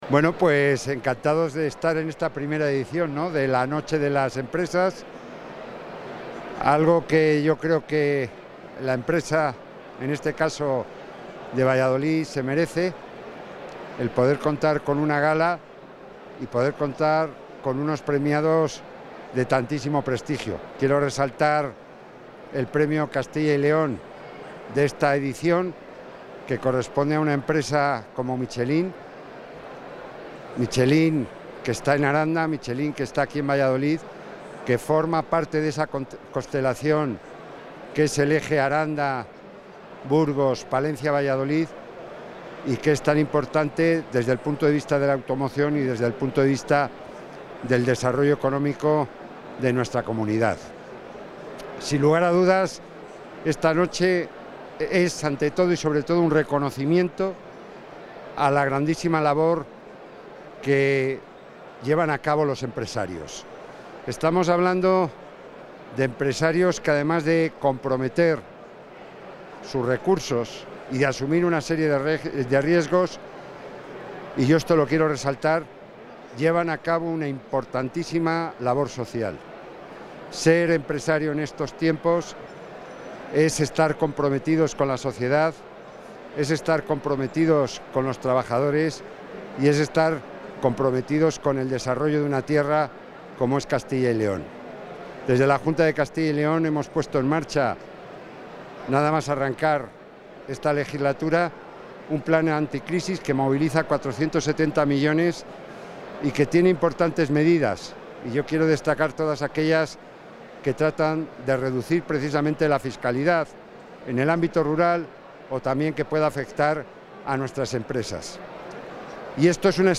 El consejero de la Presidencia, Jesús Julio Carnero, ha participado esta tarde, en Valladolid, en la entrega de Premios CEOE Valladolid 2021...
Intervención del consejero de la Presidencia.